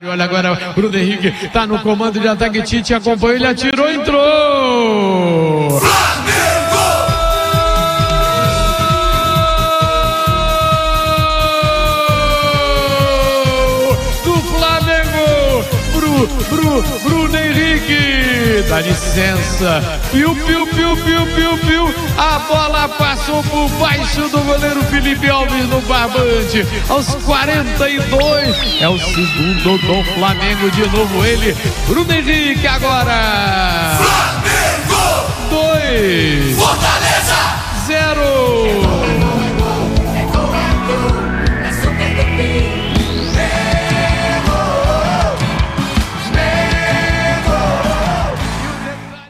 Ouça os gols da vitória do Flamengo sobre o Fortaleza com a narração do Garotinho